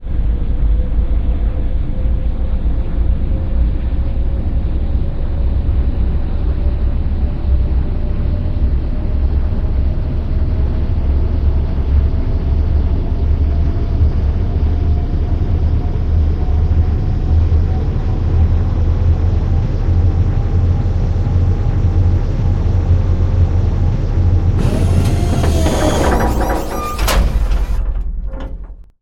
clamp1.wav